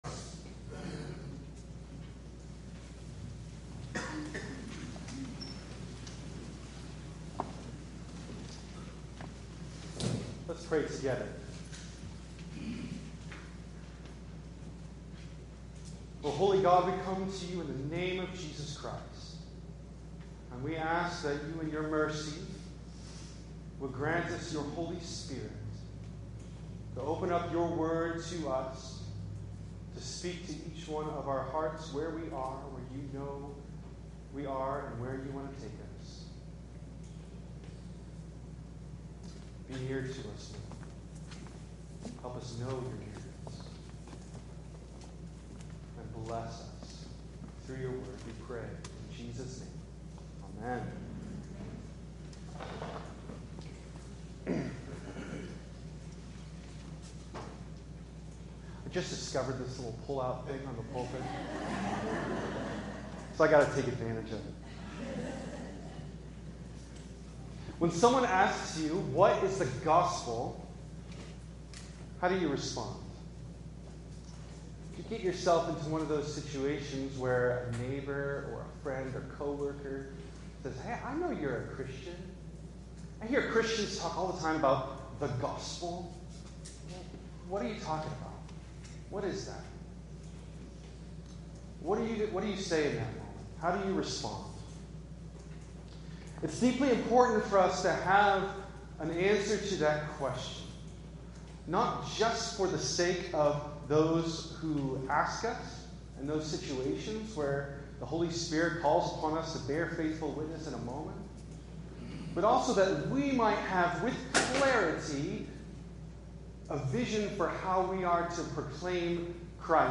In this sermon on the third Sunday of Easter